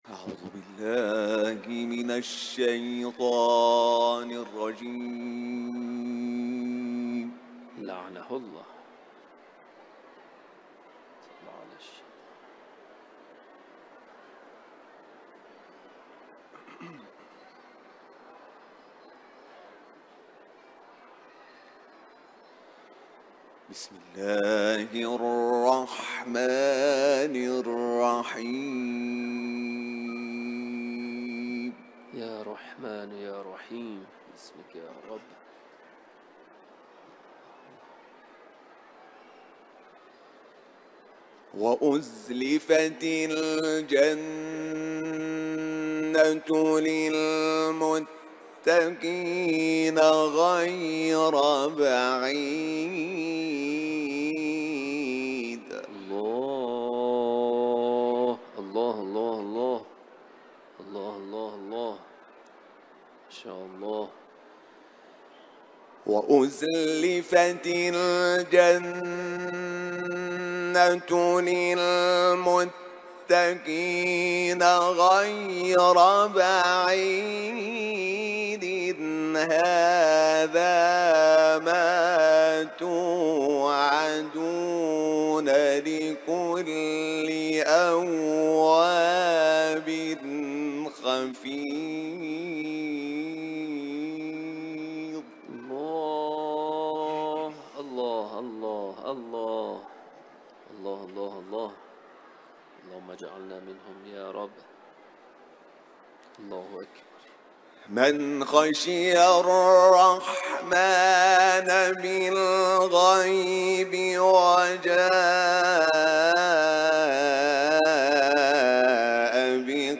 Uluslararası Kur’an kârisi